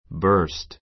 burst bə́ː r st バ ～ スト